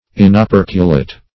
Search Result for " inoperculate" : The Collaborative International Dictionary of English v.0.48: Inopercular \In`o*per"cu*lar\, Inoperculate \In`o*per"cu*late\, a. (Zool.)